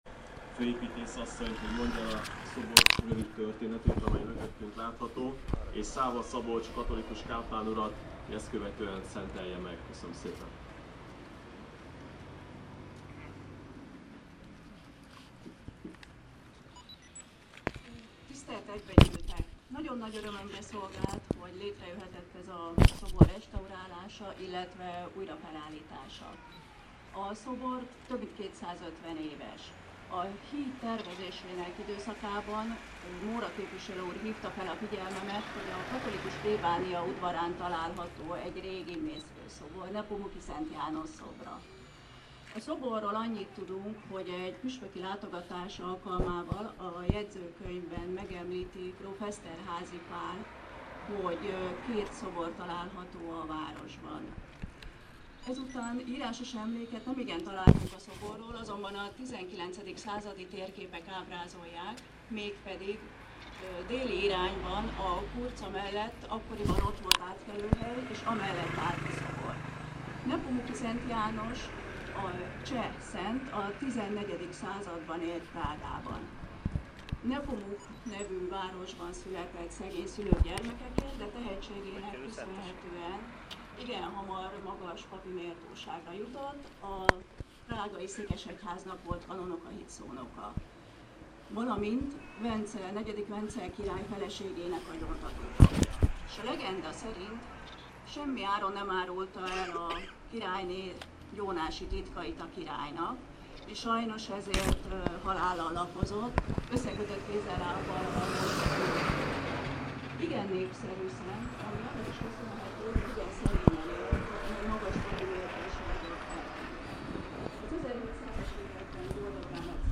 Híd- és szoboravatás a város szívében